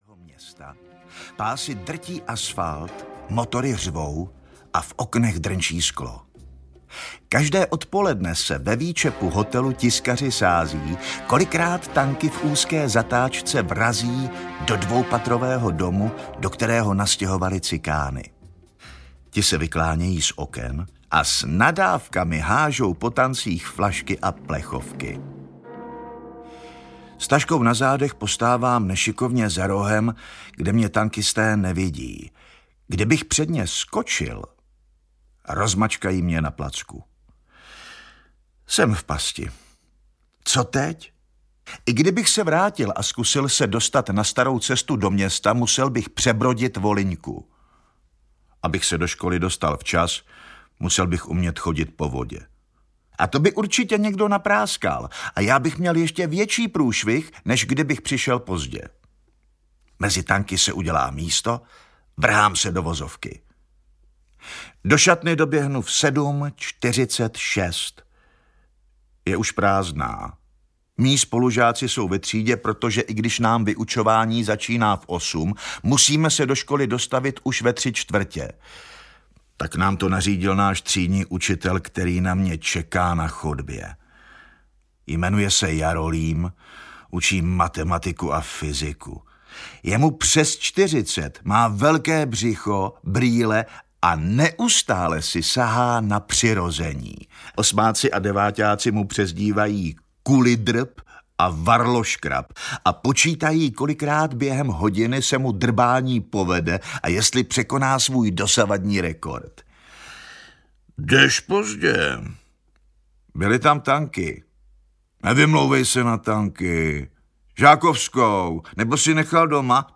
Zítra přijde Olah audiokniha
Ukázka z knihy